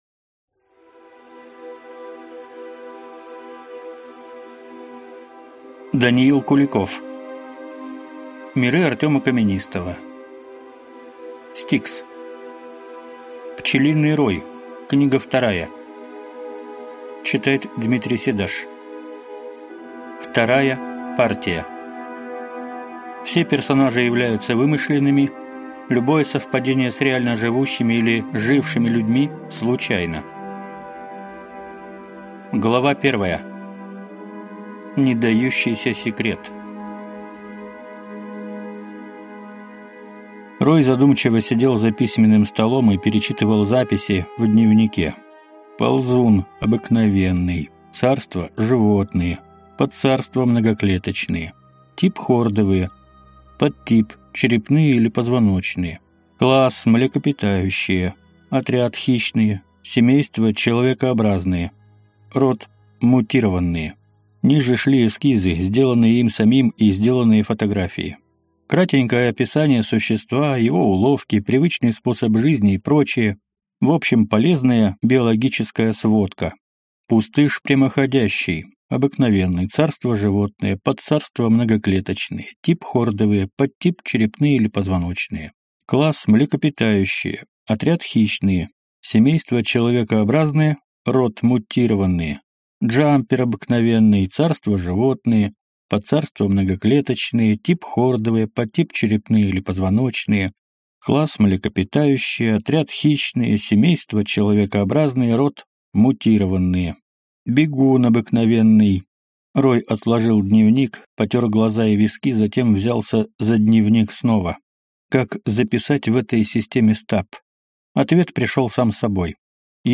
Аудиокнига S-T-I-K-S. Пчелиный Рой. Книга 2. Вторая партия | Библиотека аудиокниг